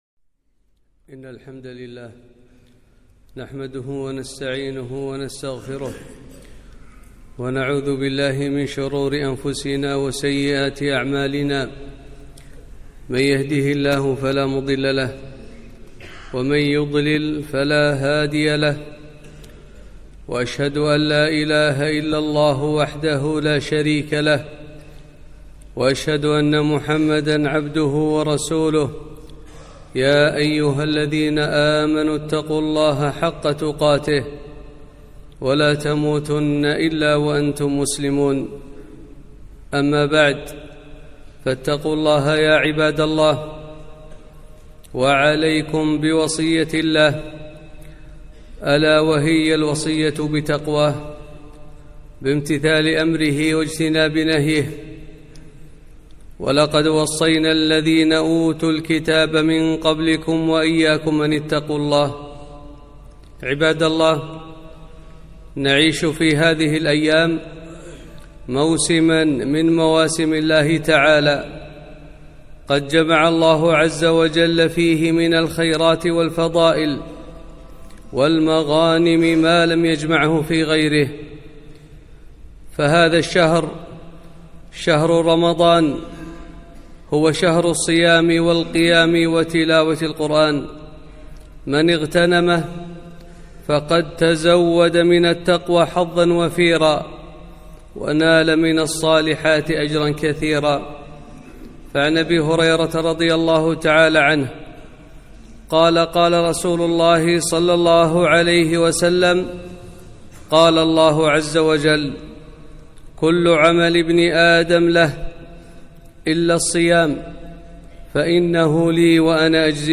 خطبة - شهر رمضان فضائل ومبشرات